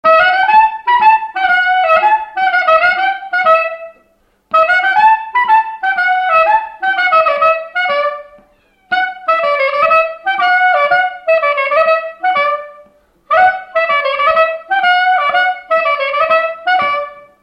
Résumé instrumental
danse-jeu : quadrille : trompeuse
Pièce musicale inédite